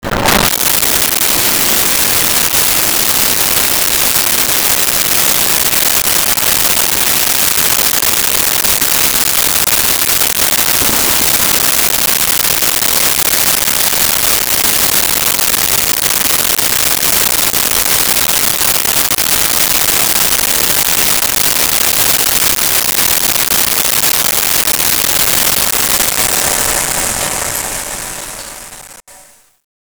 Toilet Flush 2
toilet-flush-2.wav